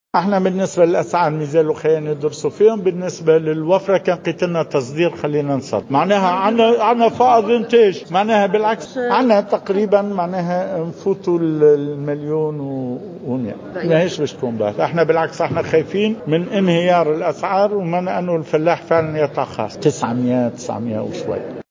وشدّد الزار في تصريح للجوهرة أف أم على هامش ندوة صحفية ، إنعقدت اليوم لتقديم كتاب "الفلاحة هي الحل"، على ضرورة دعم الإستثمار في القطاع الفلاحي ، مبرزا أن الكتاب تضمن أبرز الحلول المقترحة للنهوض بالقطاع الفلاحي، وسيتم مدّ الحكومة بهذا الكتاب.